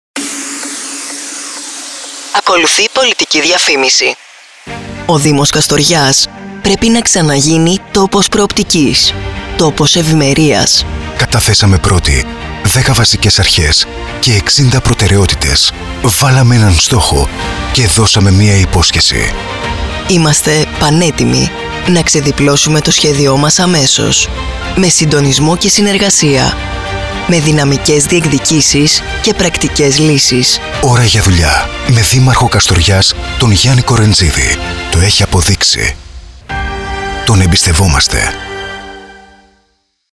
Και τρίτο ραδιοφωνικό σποτ στον “αέρα” από τον Συνδυασμό “ώρα για δουλειά”